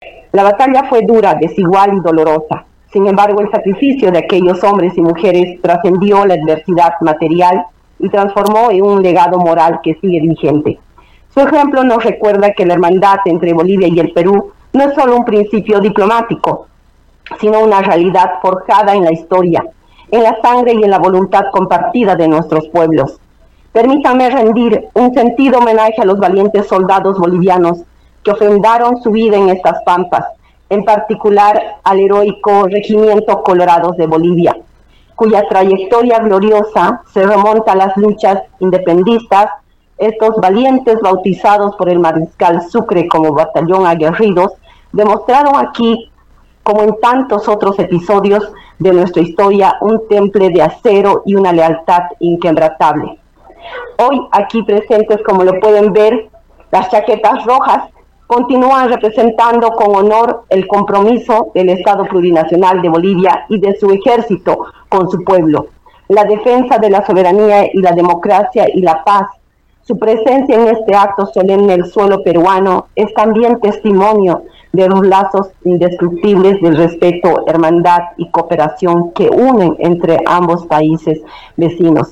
Eva Gloria Chuquimia Mamani, encargada de negocios de Bolivia en Perú, durante la ceremonia de los 145 años de la Batalla del Campo de la Alianza destacó un legado moral que une a ambos países.